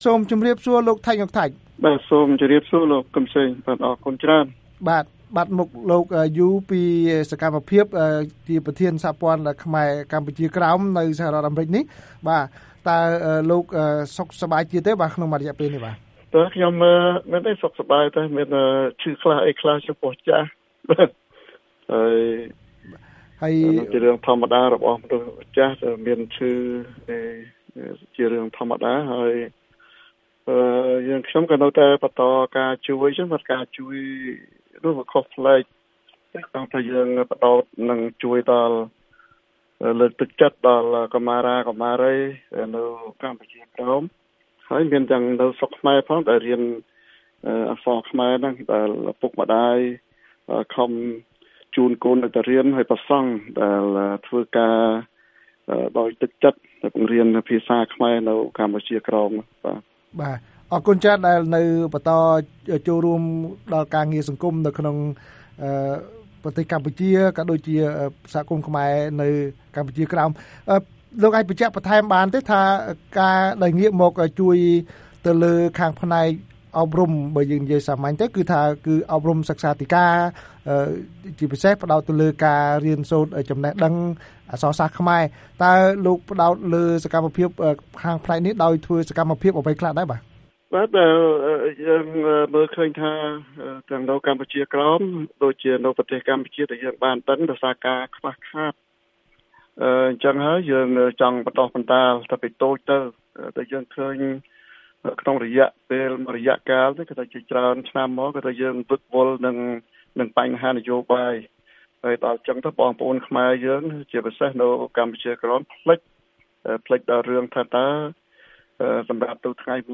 បទសម្ភាសន៍៖ សកម្មជនថាកុមារខ្មែរក្រោមចង់សិក្សាអក្សរខ្មែរ ប៉ុន្តែខ្វះខាតសៀវភៅ